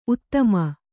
♪ uttama